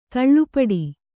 ♪ taḷḷu paḍi